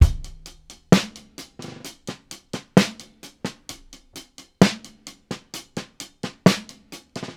• 130 Bpm Drum Loop Sample C# Key.wav
Free breakbeat - kick tuned to the C# note. Loudest frequency: 1047Hz
130-bpm-drum-loop-sample-c-sharp-key-Db3.wav